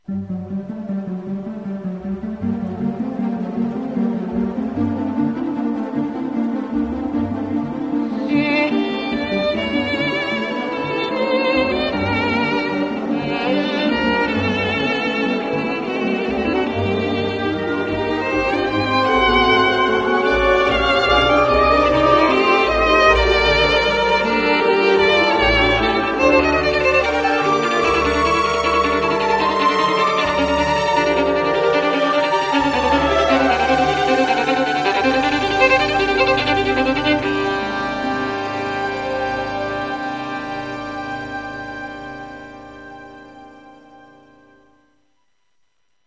Theme tune : The
violin-based tune used for the 1980s tv series.